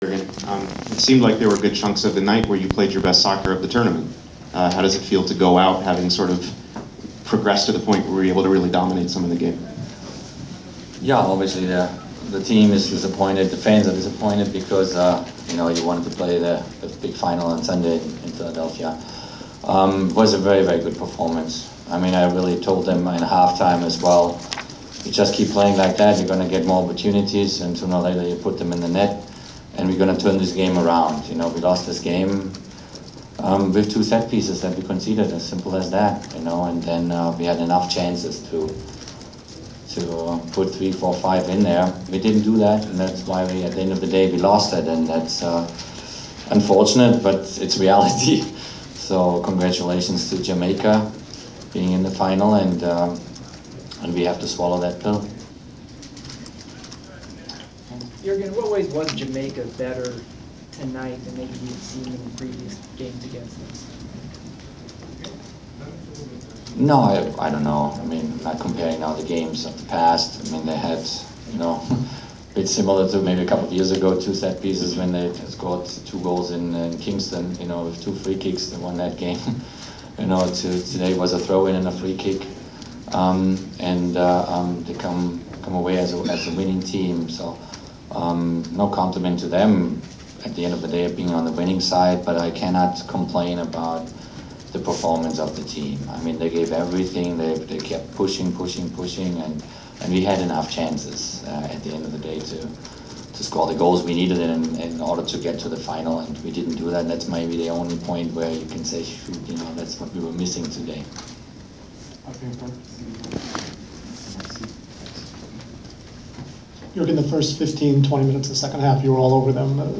Inside the Inquirer: Postmatch presser of USA Men’s Soccer head coach Jurgen Klinsmann 7.22.15
The Sports Inquirer attended the postmatch presser of United States Men’s Soccer head coach Jurgen Klinsmann following his team’s 2-1 loss to Jamaica in the semifinals of the Gold Cup in Atlanta on July 22.